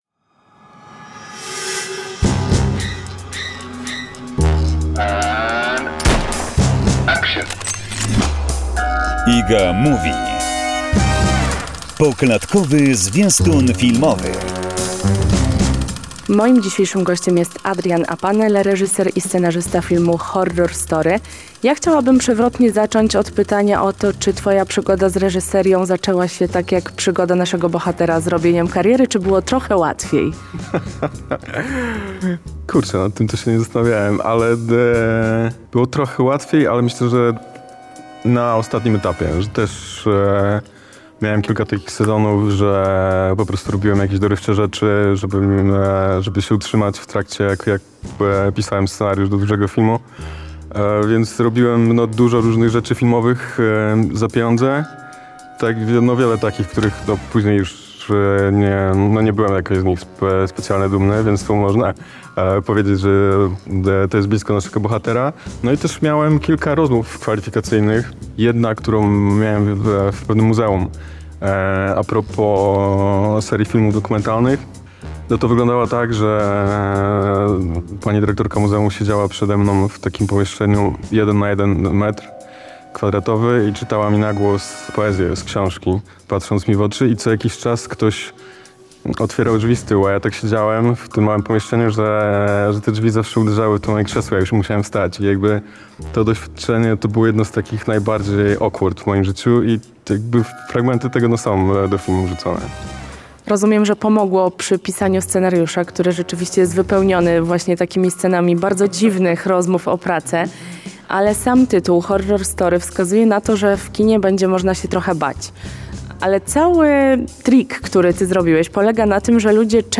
Rozmowa o filmie „Horror Story”.